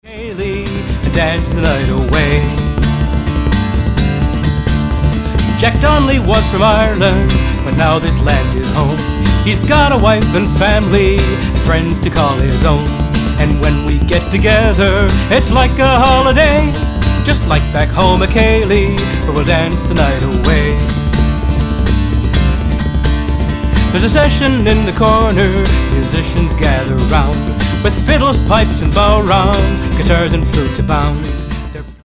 - barn burner opener with reels at the end